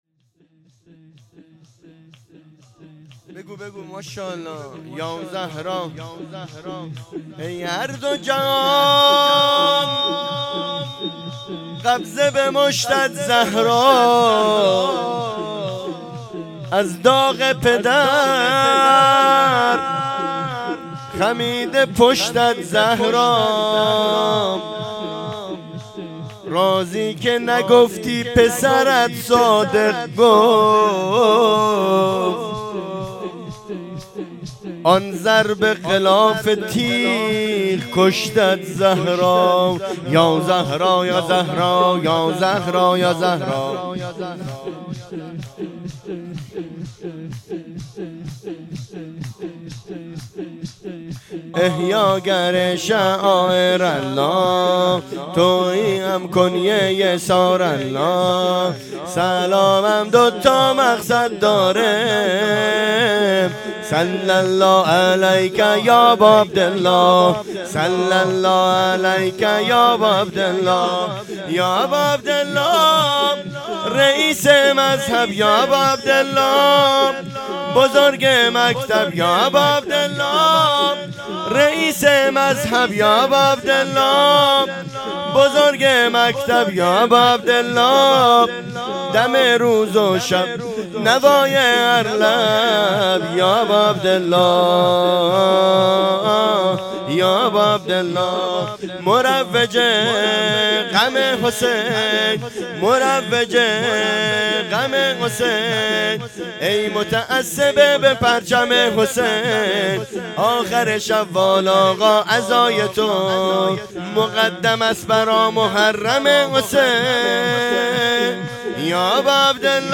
شهادت امام صادق علیه السلام 1404
شور